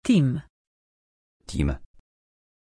Aussprache von Tim
pronunciation-tim-pl.mp3